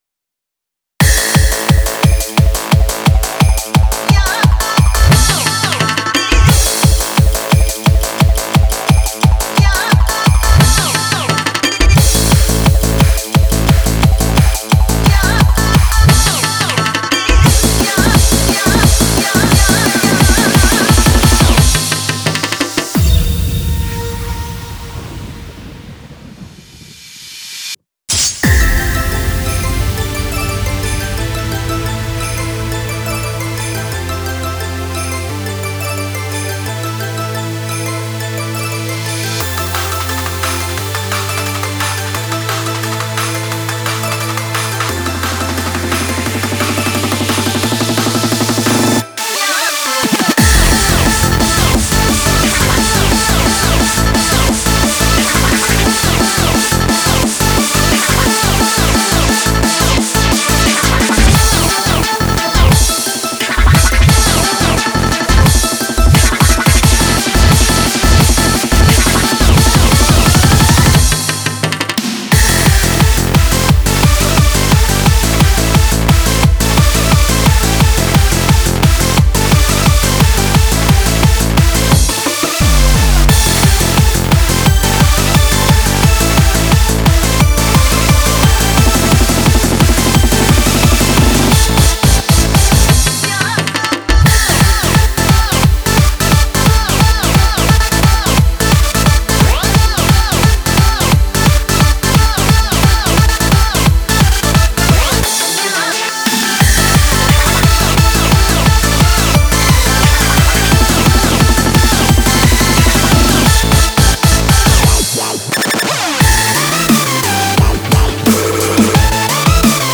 J-Core